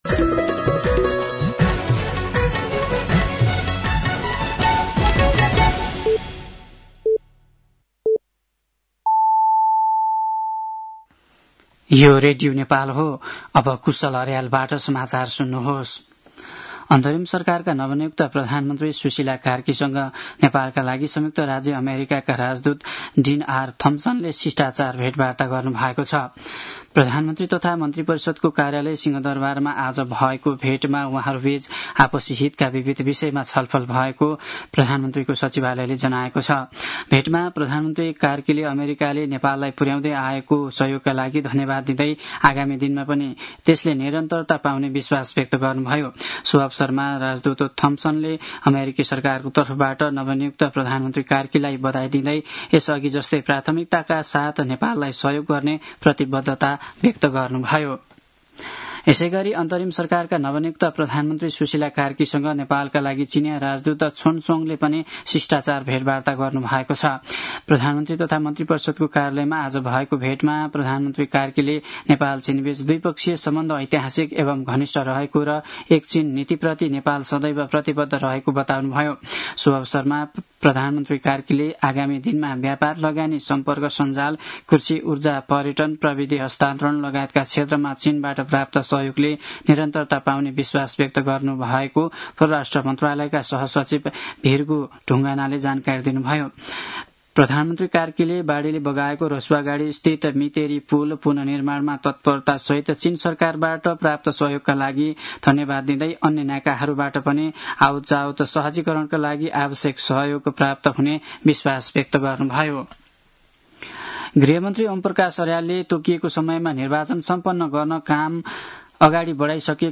साँझ ५ बजेको नेपाली समाचार : २ असोज , २०८२
5.-pm-nepali-news-1-4.mp3